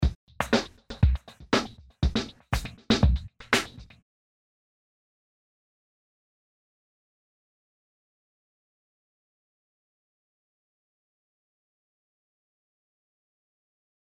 更に各ノートのベロシティ値を調整してアクセントやダイナミクスを変更し、異なるグルーヴ感を作り出すことができます。
図4：図3の状態からベロシティ値を変更した状態。